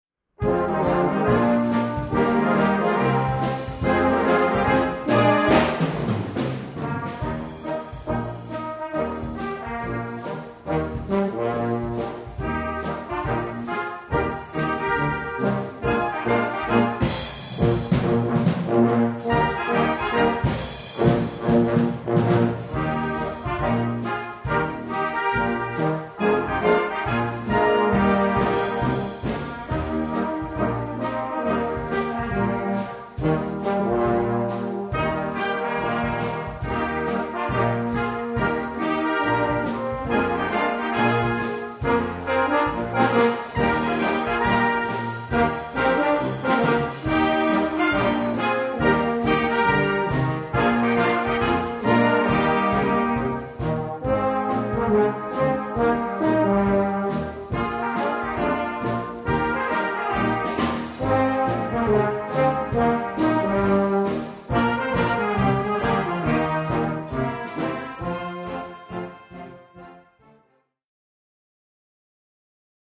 Komponist: Traditionell
Besetzung: Blasorchester